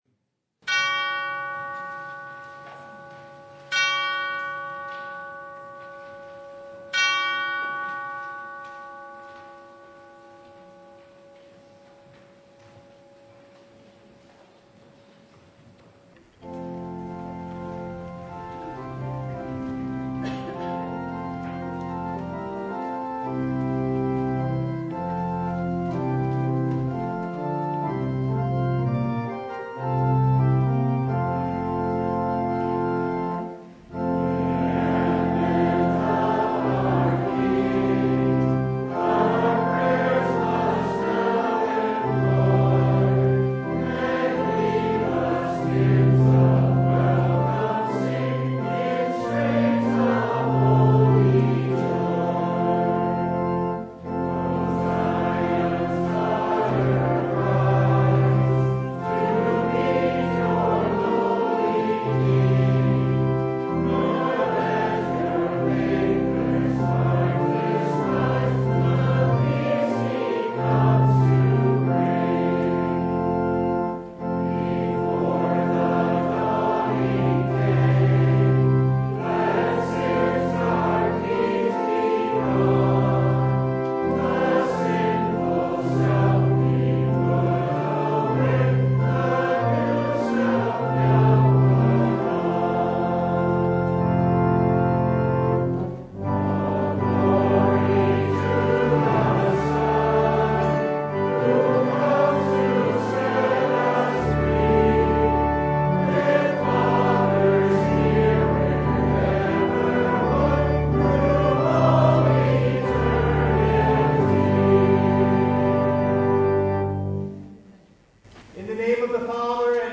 Luke 19:28-40 Service Type: Sunday Why does Advent begin with adult Jesus riding a colt into Jerusalem?